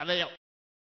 File File history File usage WC98_A-laeo!.oga  (Ogg Vorbis sound file, length 1.0 s, 71 kbps) Licensing [ edit ] This is a sound clip from a copyrighted video game.